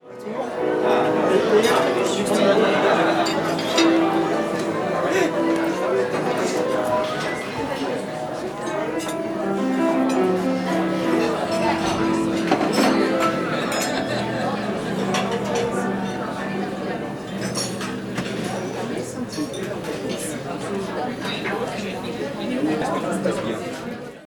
ambience